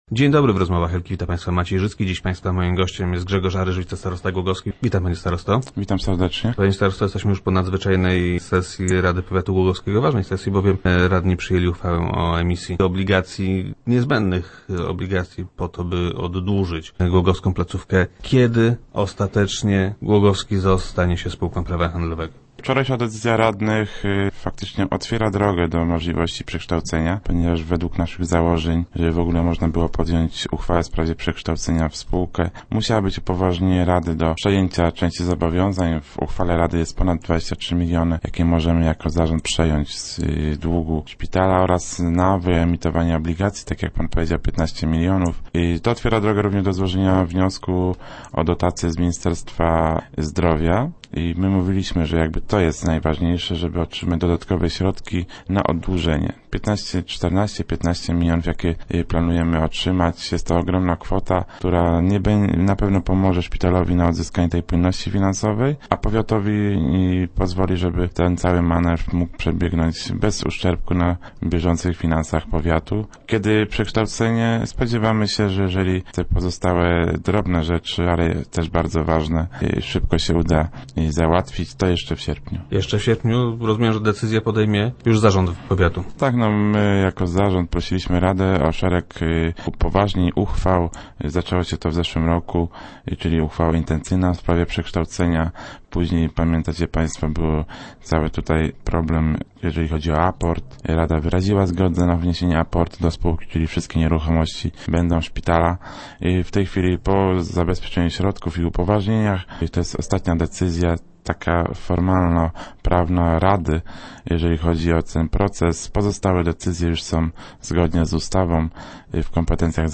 - Myślę, że stanie się to jeszcze w tym miesiącu – twierdzi wicestarosta Grzegorz Aryż, który był gościem Rozmów Elki.